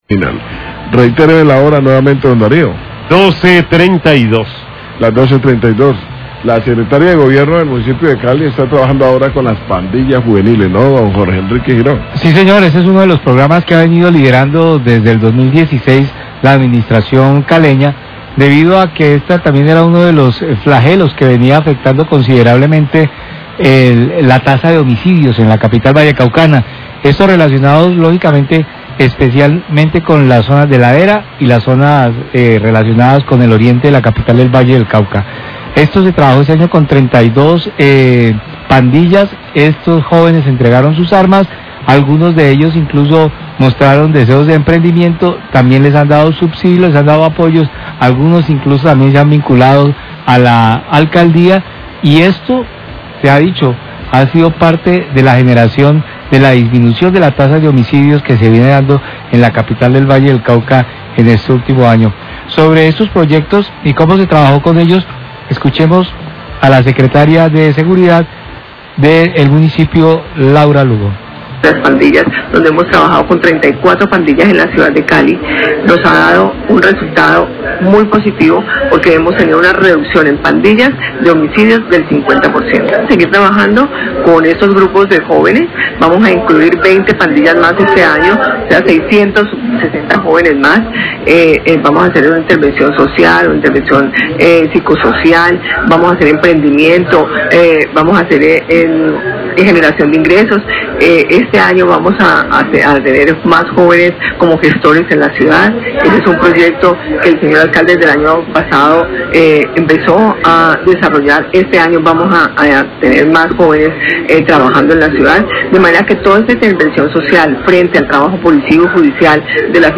NOTICIAS DE CALIDAD